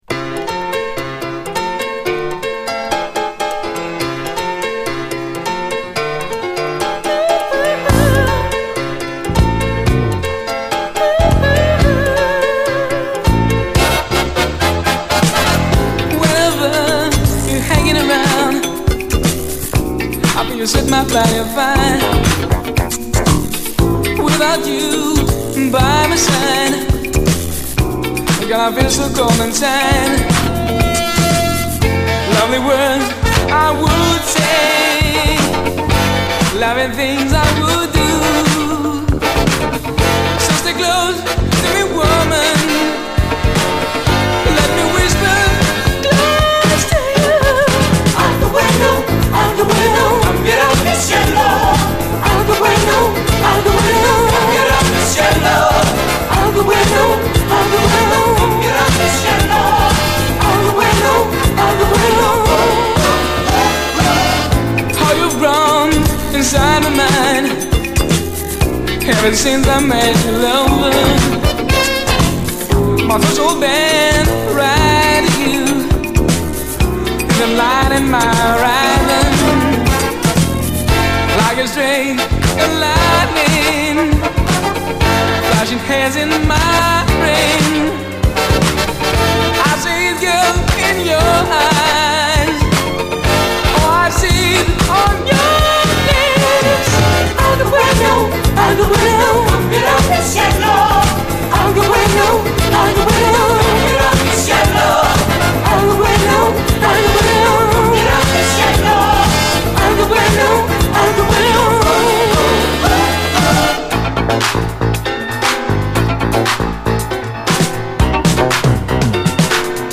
めちゃ切ないスウィート・ラテン・ソウル
ファンキーにドライヴする
STEREO、U.S.ORIGINAL盤！